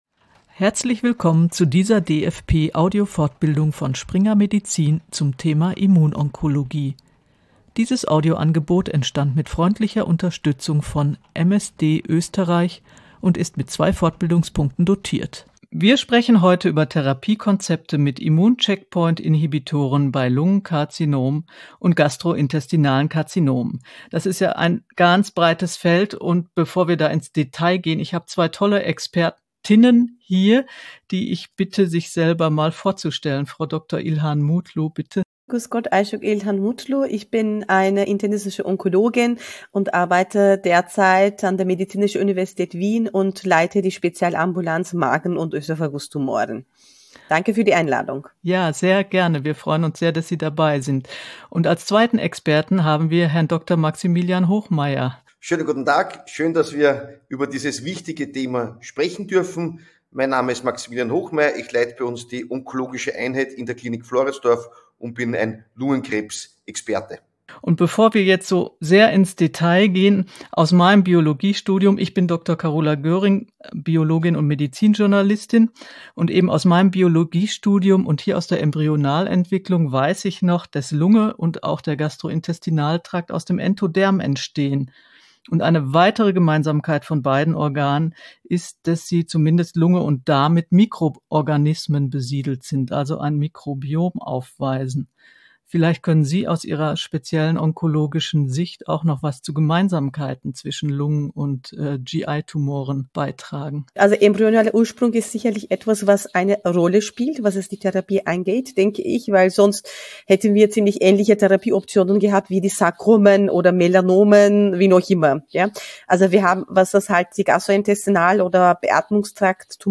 Hörgang Exklusiv / Expertengespräch über Tumoren der Lunge und des Gastrointestinaltraktes